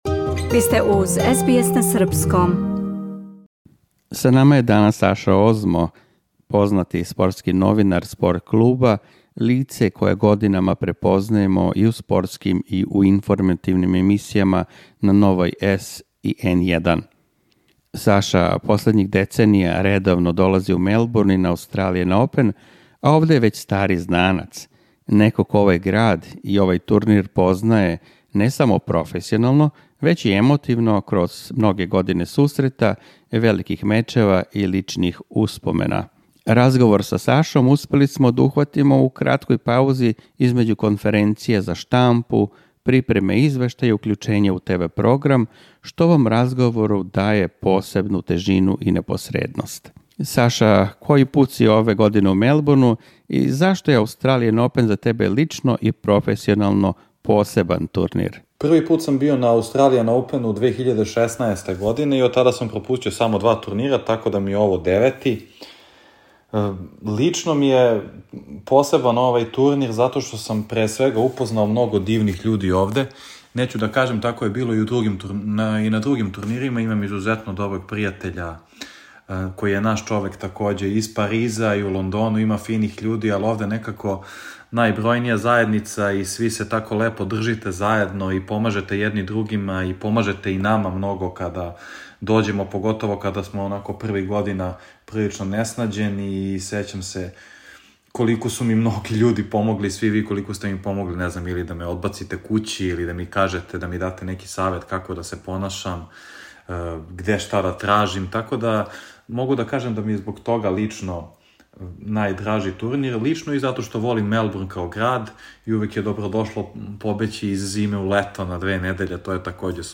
Разговор је настао у краткој паузи између конференција за штампу и укључења уживо, што му даје посебну непосредност и аутентичност.